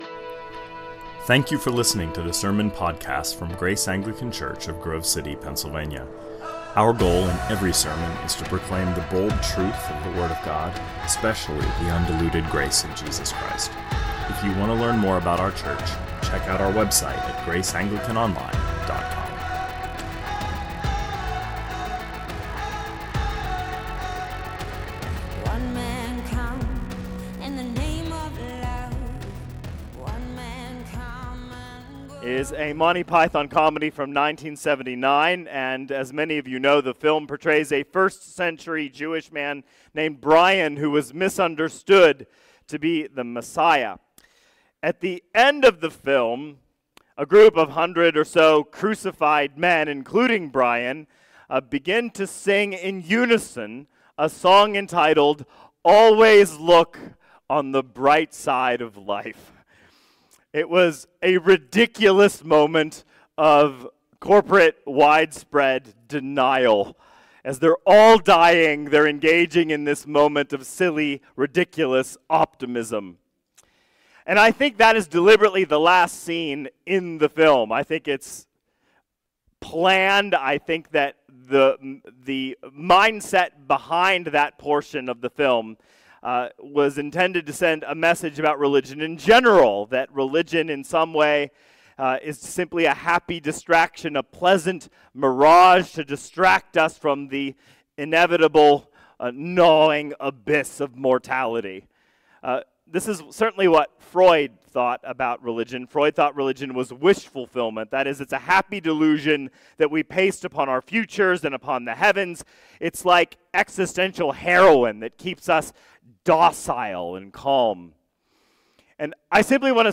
2024 Sermons